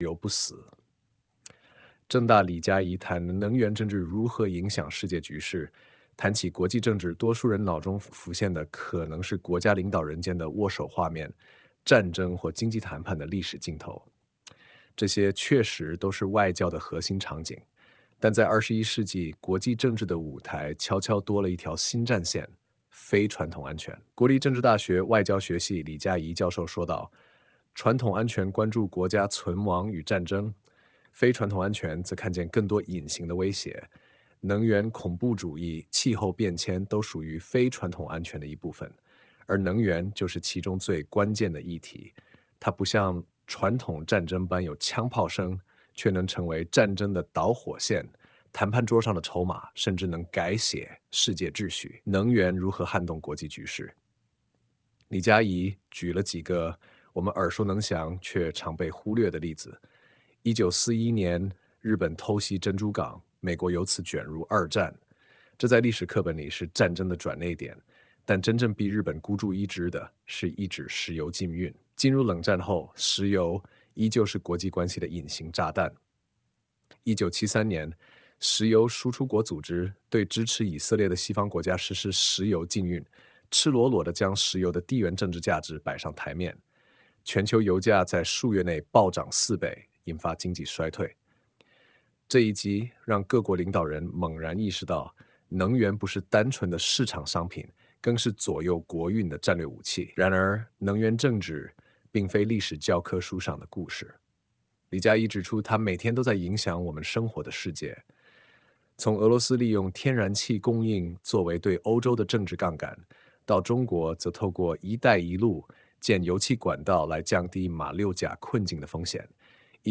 全文朗讀 談起「國際政治」，多數人腦中浮現的可能是國家領導人間的握手畫面、戰爭或經 […]